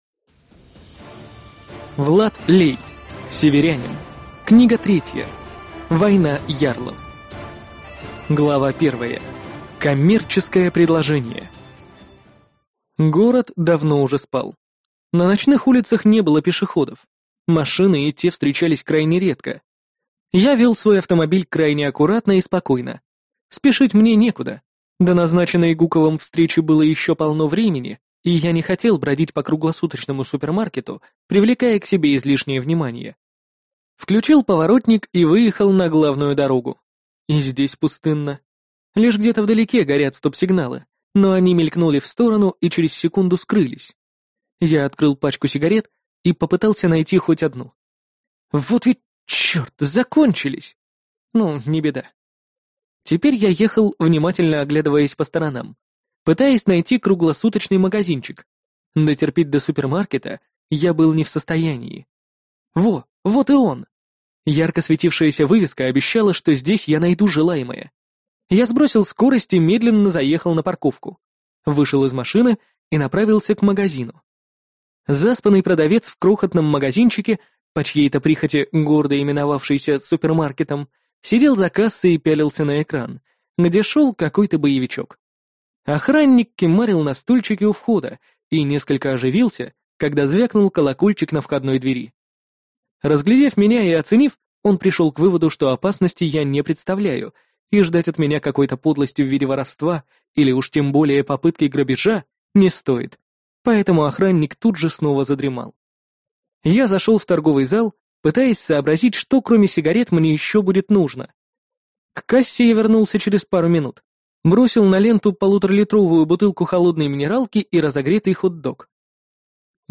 Аудиокнига Северянин. Книга 3. Война ярлов | Библиотека аудиокниг